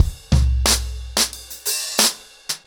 ROOTS-90BPM.21.wav